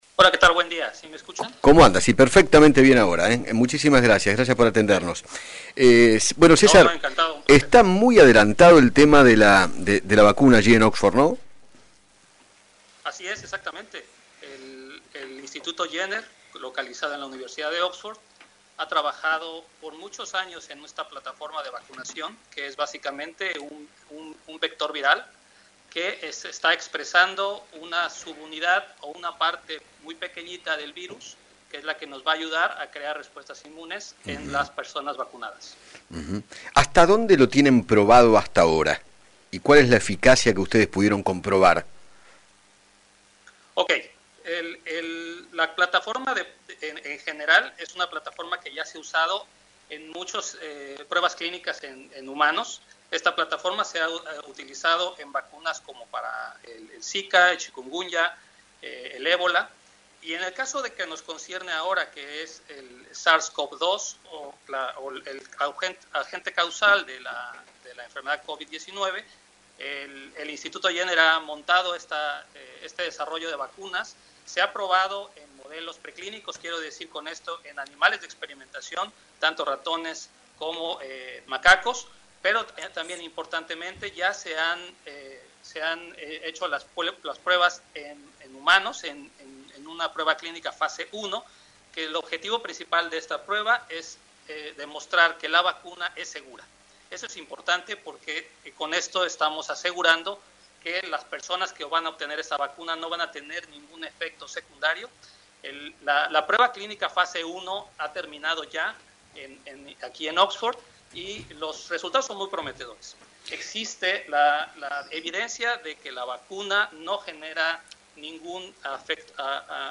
dialogó con Eduardo Feinmann sobre le evolución de la vacuna que está desarrollando dicha institución para combatir el coronavirus.